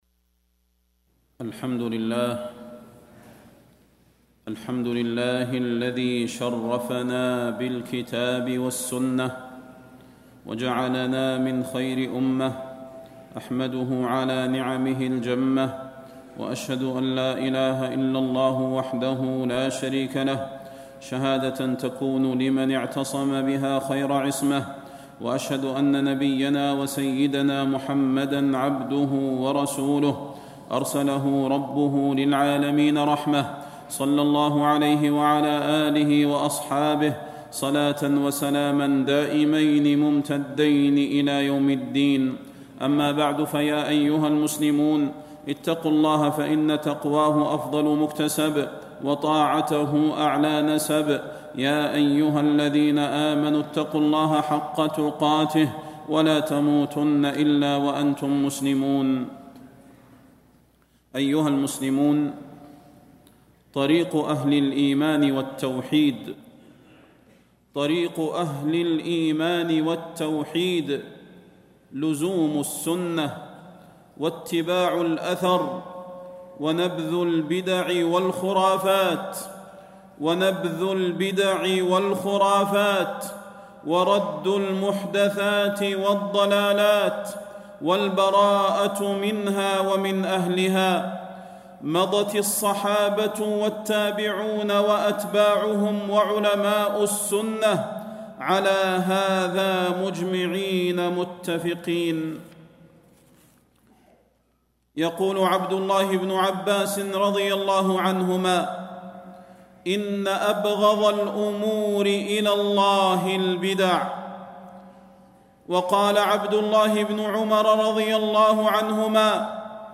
تاريخ النشر ١٩ محرم ١٤٣٥ هـ المكان: المسجد النبوي الشيخ: فضيلة الشيخ د. صلاح بن محمد البدير فضيلة الشيخ د. صلاح بن محمد البدير اتبعوا ولا تبتدعوا